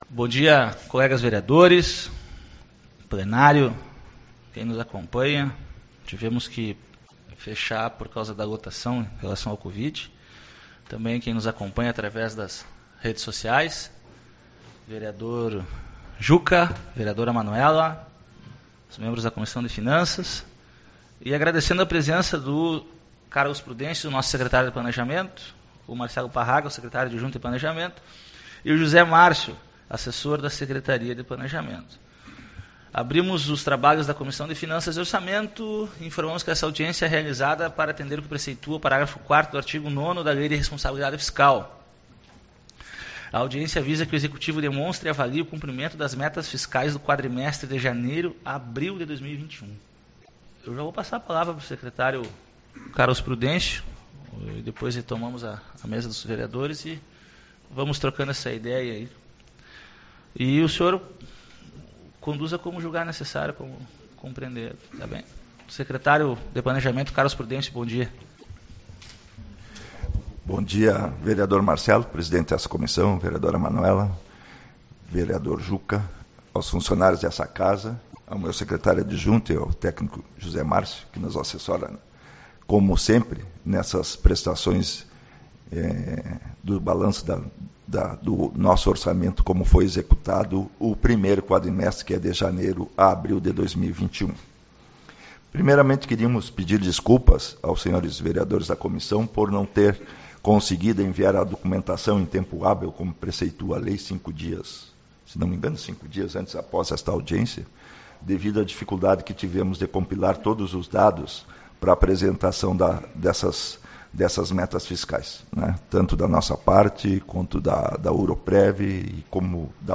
28/05 - Audiência Pública-Metas Fiscais